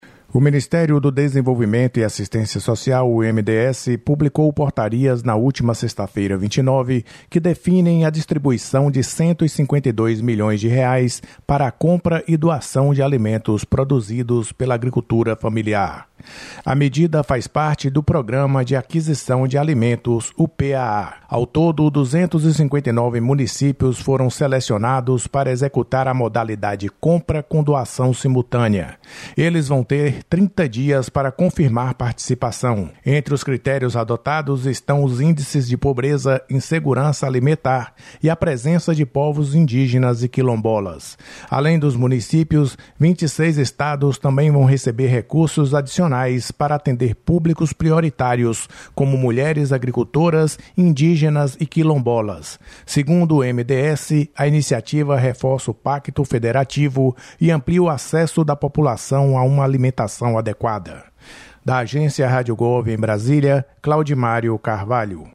Convocação de Rede
09/12/23 - Pronunciamento do Ministro dos Direitos Humanos e da Cidadania Silvio Almeida